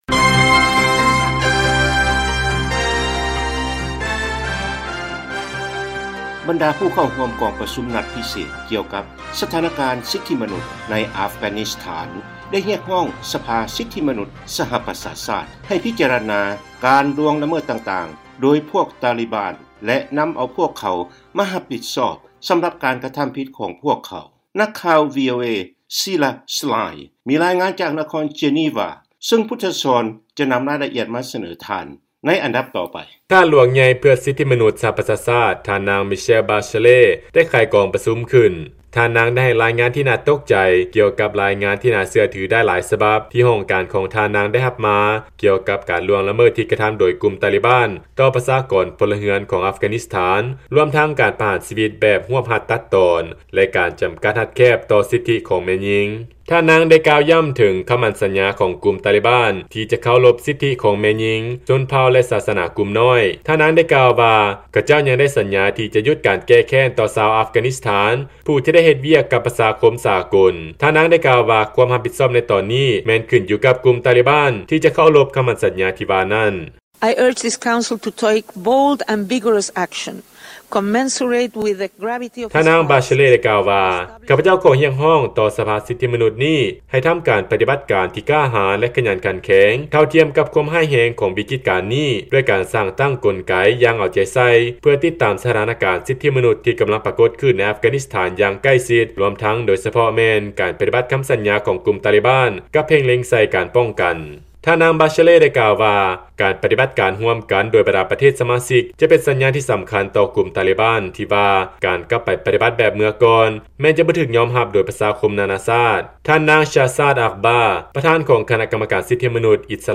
ຟັງລາຍງານ ກຸ່ມສົ່ງເສີມສິດທິມະນຸດ ຮຽກຮ້ອງ ສປຊ ໃຫ້ນຳເອົາກຸ່ມຕາລີບານ ມາລົງໂທດ ສຳລັບການກໍ່ອາຊະຍະກຳໃນ ອັຟການິສຖານ